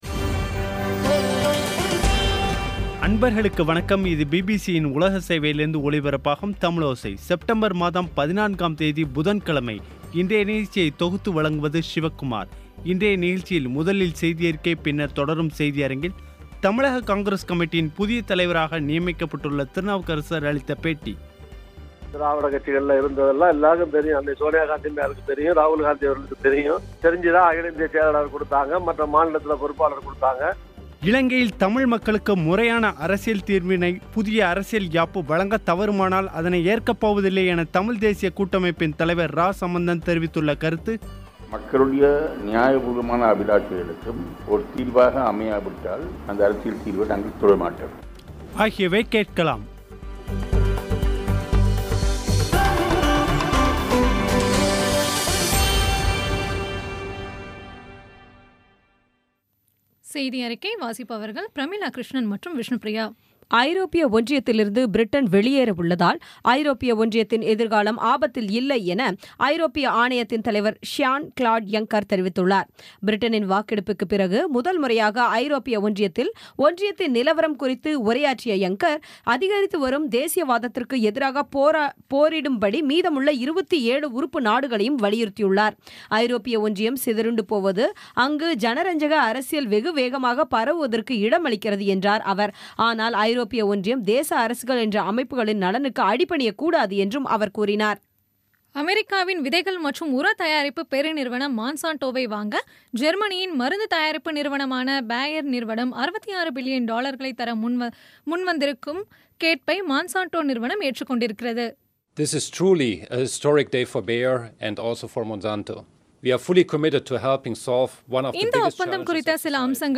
இன்றைய நிகழ்ச்சியில் முதலில் செய்தியறிக்கை, பின்னர் தொடரும் செய்தியரங்கில்
தமிழக காங்கிரஸ் கமிட்டியின் புதிய தலைவராக நியமிக்கப்பட்டுள்ள திருநாவுக்கரசர் அளித்த பேட்டி